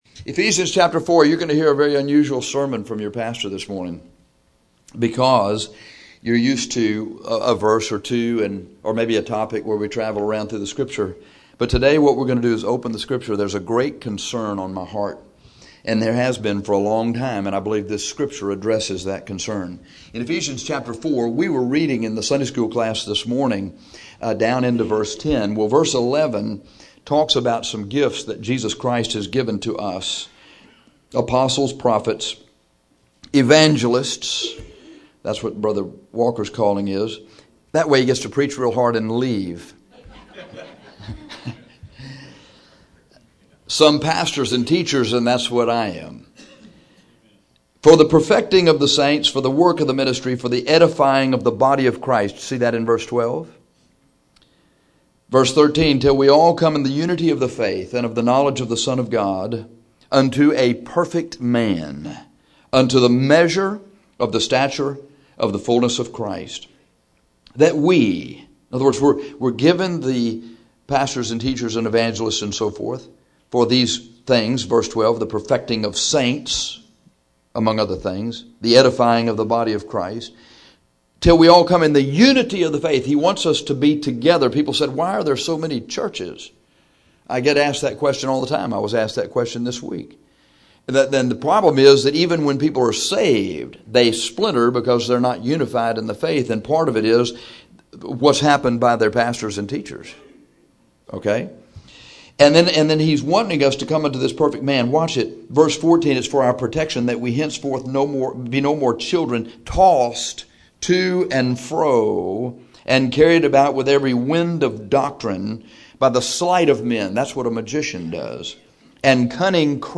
Rather than preach this text to simply answer the question why Christians fall, we will preach this passage to show you how to keep from falling.